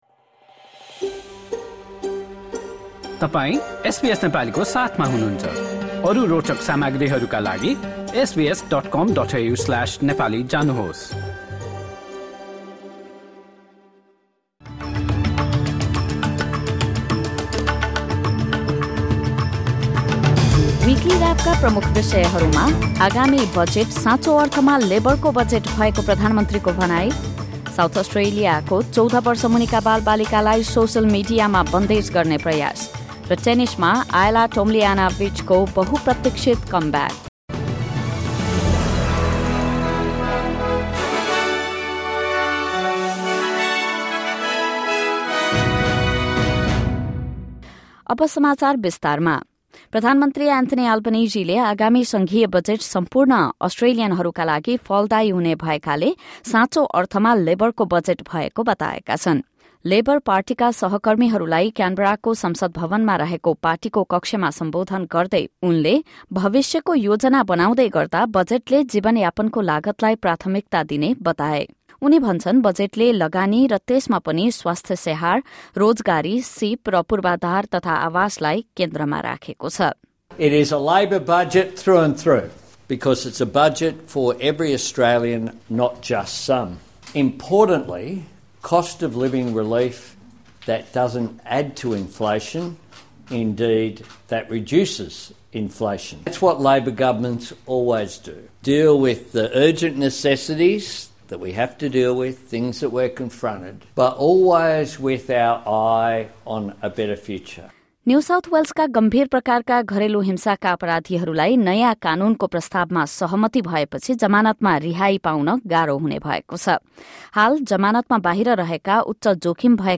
सङ्घीय बजेटले सबै अस्ट्रेलियनहरूलाई समेट्ने भएकोले यो साँचो अर्थमा 'लेबरको बजेट' भएको प्रधानमन्त्री एन्थोनी आल्बनिजीको भनाइ रहेको छ। यो लगायत गत एक हप्ता यताका प्रमुख घटनाहरू बारे एसबीएस नेपालीबाट समाचार सुन्नुहोस्।